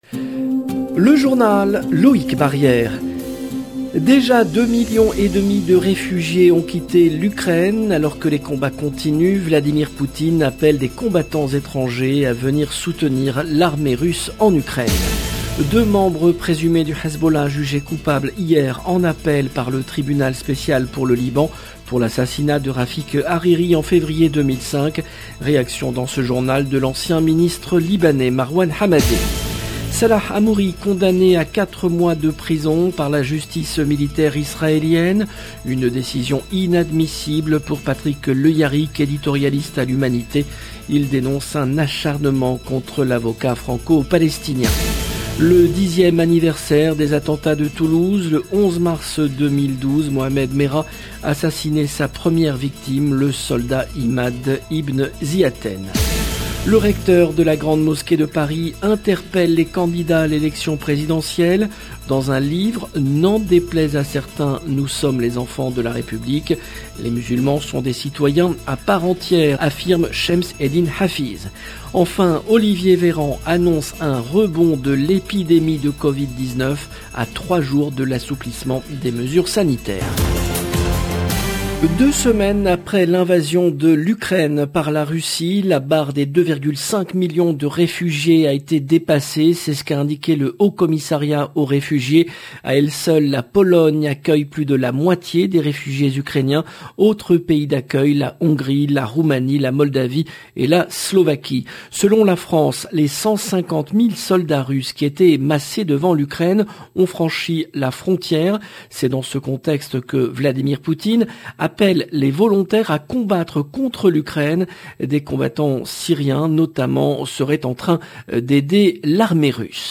Réaction dans ce journal de l’ancien ministre libanais Marwan Hamadé.